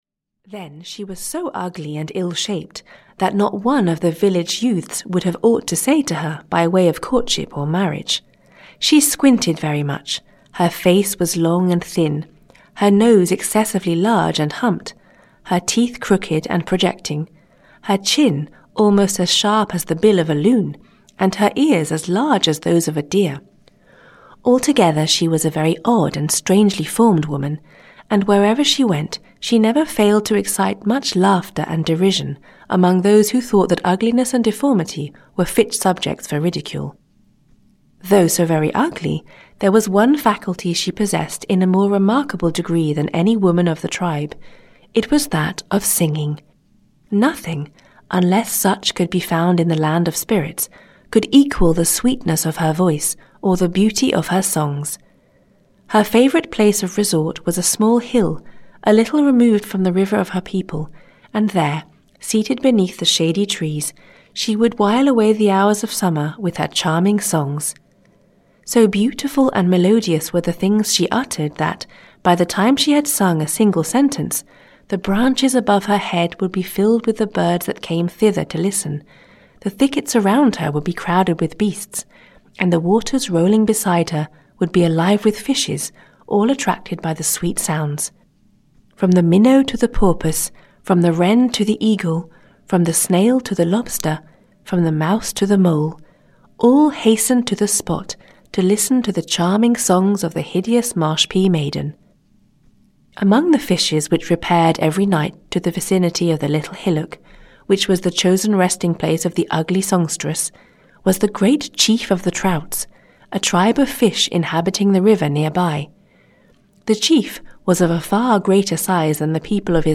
3 American Indian Stories (EN) audiokniha
Ukázka z knihy